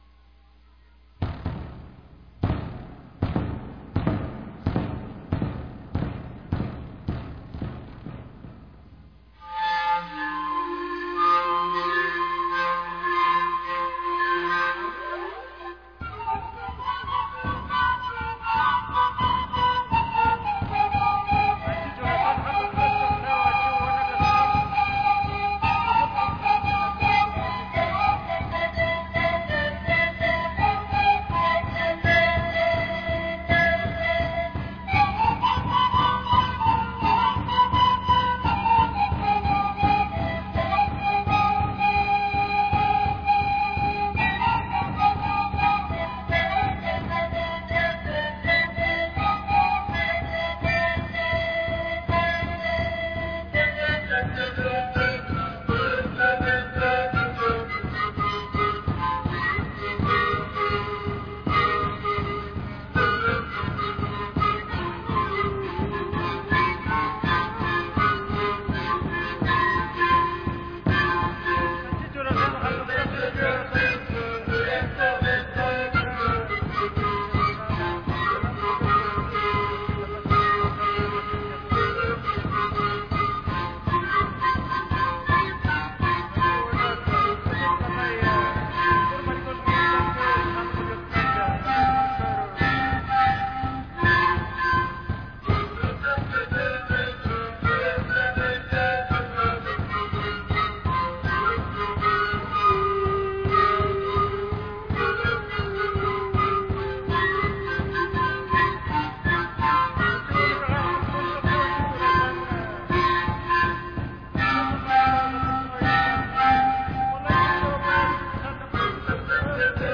Jach'a Sikus